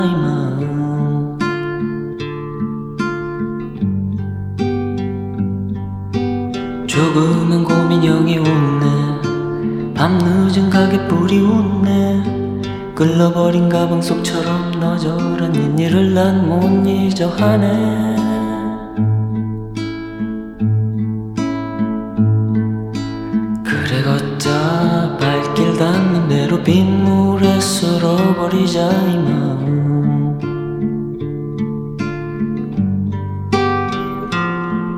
Folk Pop K-Pop
Жанр: Поп музыка / Фолк